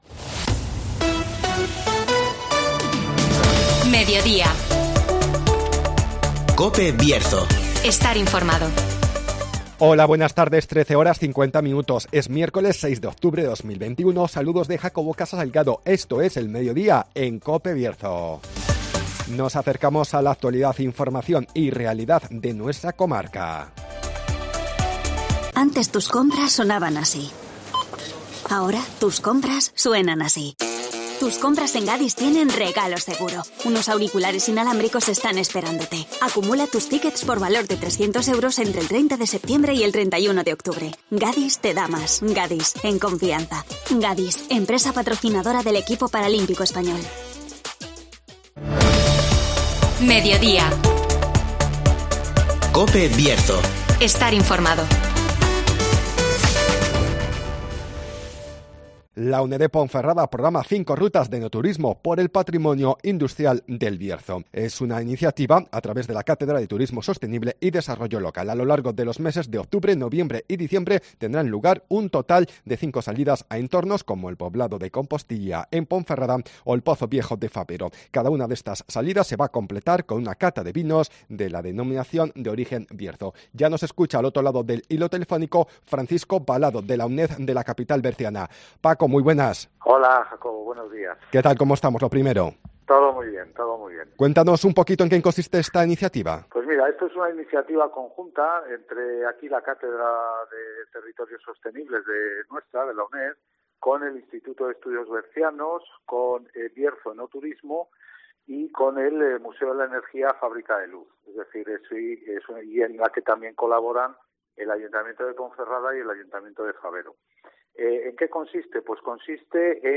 La Uned de Ponferrada programa cinco rutas de enoturismo por el patrimonio industrial del Bierzo (Entrevista